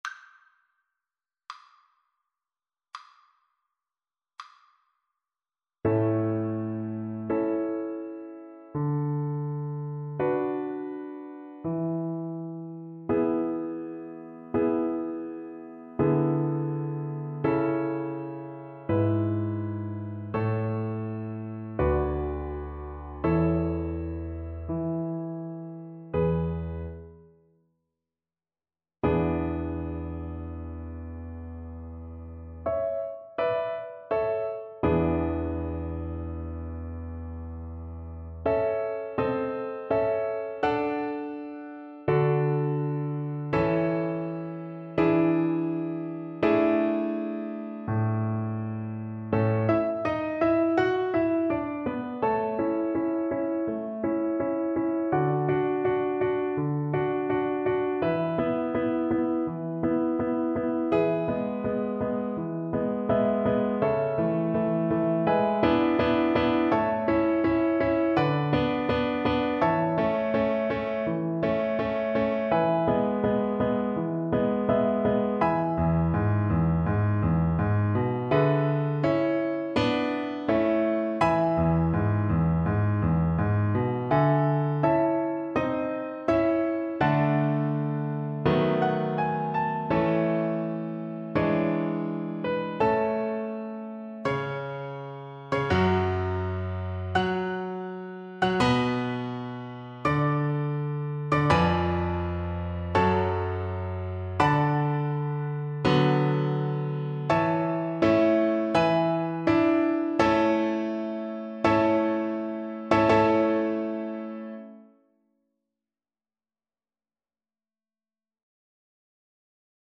Violin version
Adagio cantabile (View more music marked Adagio cantabile)
4/4 (View more 4/4 Music)
Classical (View more Classical Violin Music)